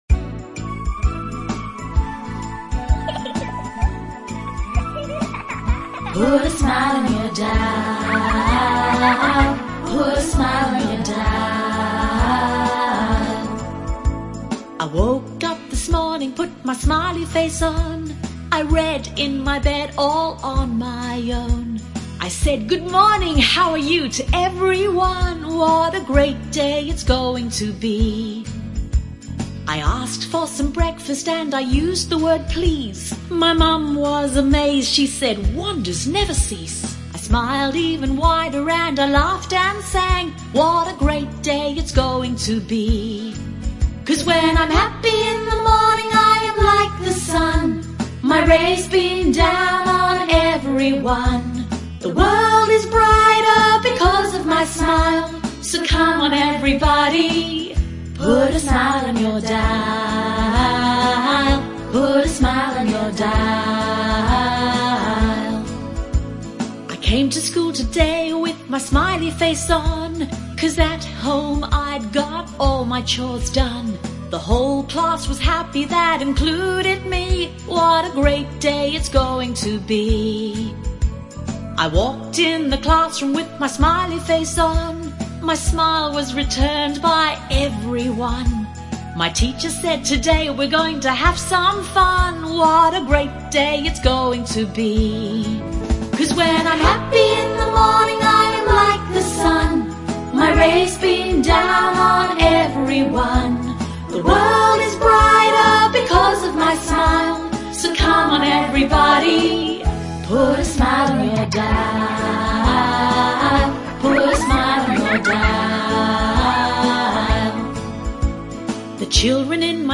Catchy tune!